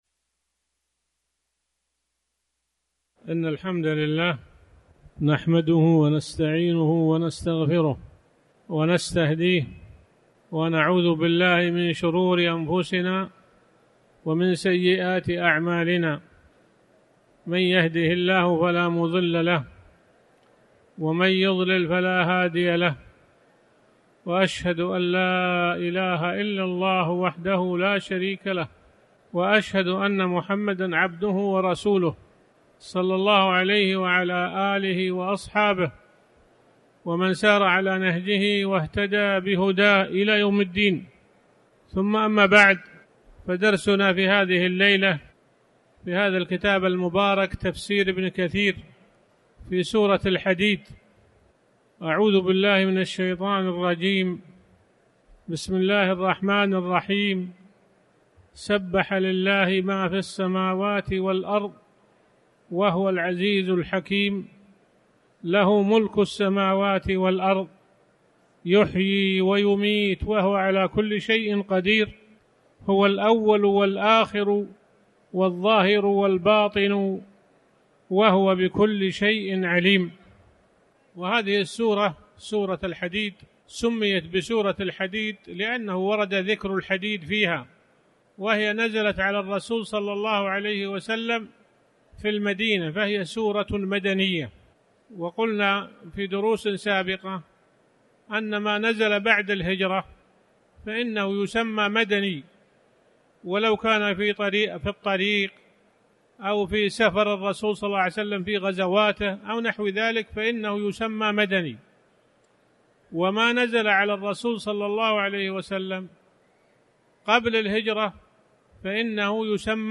تاريخ النشر ١٤ شوال ١٤٤٠ هـ المكان: المسجد الحرام الشيخ